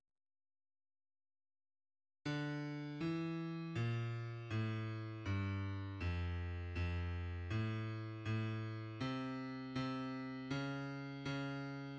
low part